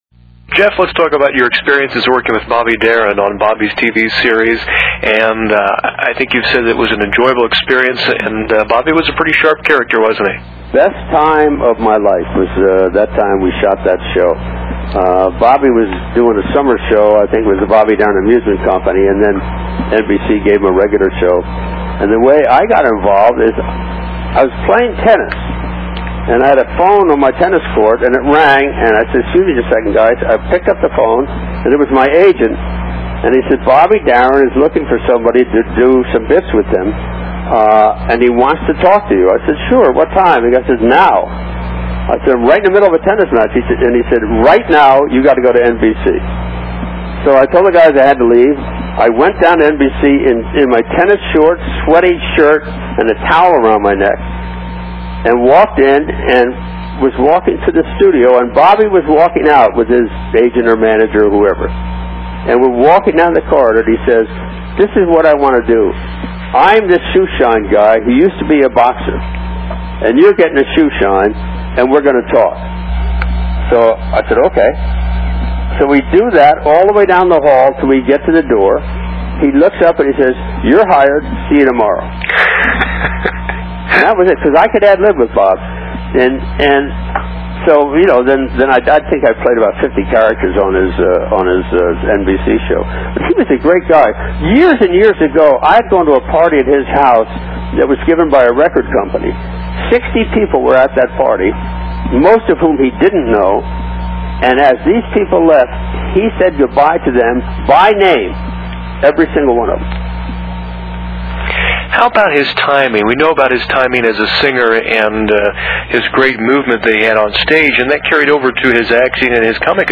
The interview is